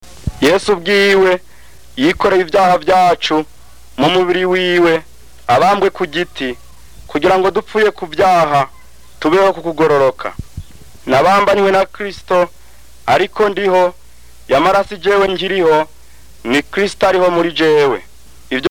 5 vowels, didn’t hear any NC clusters, didn’t hear any “special” things like clicks or ejectives…